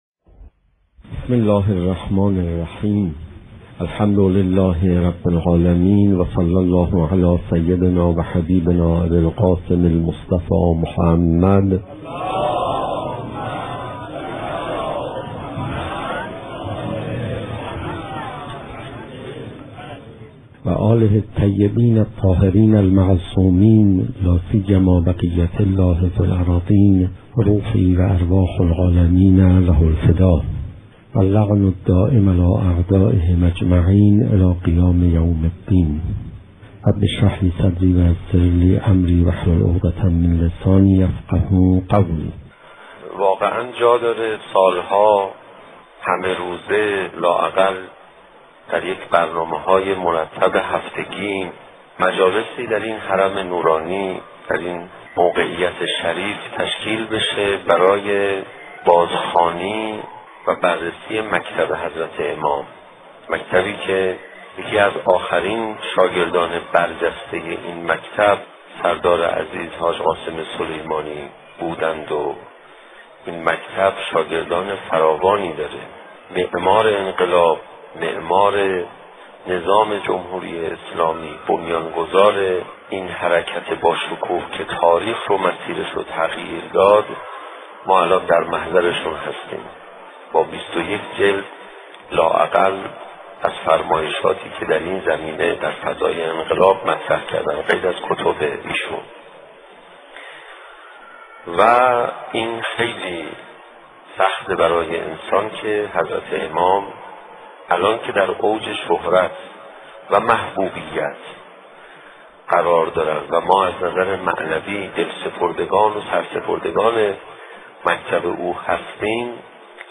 سخنرانی حجت الاسلام علیرضا پناهیان با موضوع دین تنها عامل آبادانی دنیا